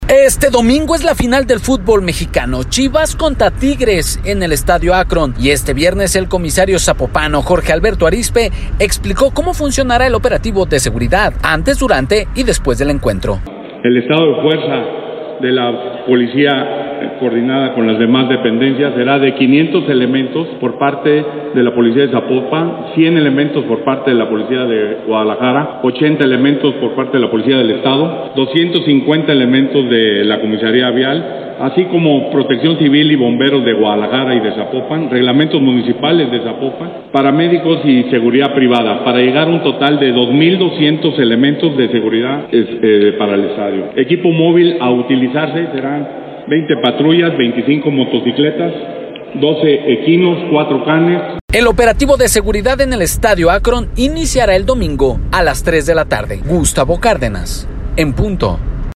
Este domingo es la final del fútbol mexicano, Chivas contra Tigres en el Estadio Akron, este viernes el comisario de Zapopan, Jorge Alberto Arizpe, explicó cómo funcionará el operativo de seguridad, antes  durante y después del encuentro.